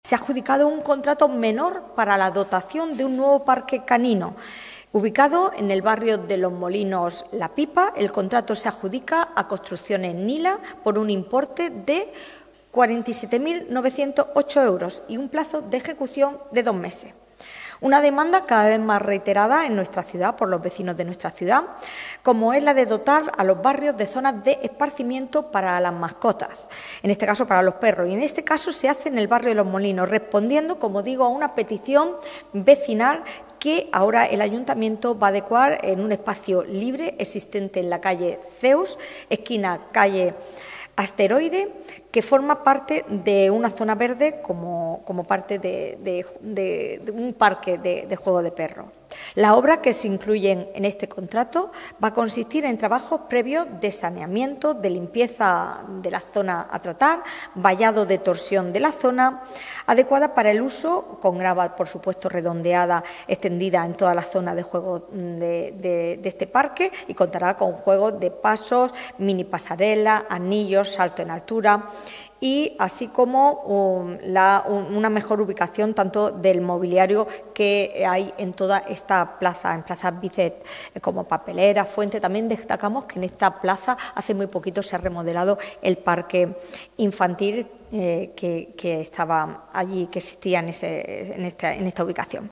La portavoz municipal y concejal de Obras Públicas del Ayuntamiento de ALmería, Sacramento Sánchez, ha dado a conocer hoy en rueda de prensa el acuerdo adoptado por la Junta de Gobierno Local para la creación de un nuevo parque canino en el barrio de Los Molinos–La Pipa, atendiendo así a una de las demandas vecinales más reiteradas en los últimos meses.